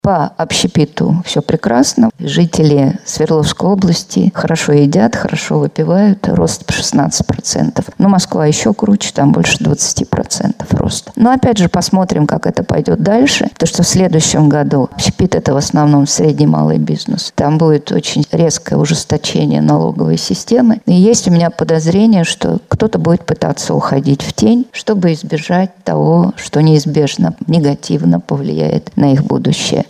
На Среднем Урале в сфере общепита в этом году наблюдался  рост 16%, по стране — чуть больше 8%. Об этом сообщила профессор МГУ Наталья Зубаревич на пресс-конференции ТАСС-Урал.